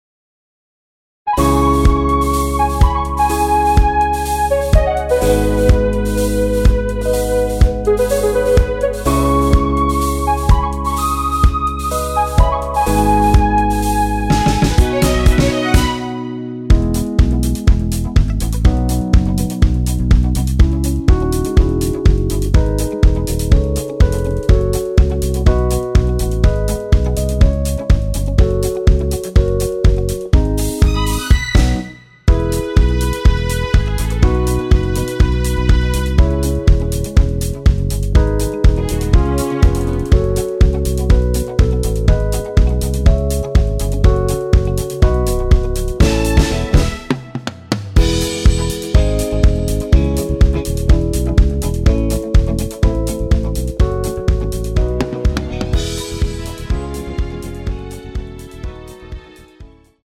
원키에서(+4)올린 MR입니다.
◈ 곡명 옆 (-1)은 반음 내림, (+1)은 반음 올림 입니다.
앞부분30초, 뒷부분30초씩 편집해서 올려 드리고 있습니다.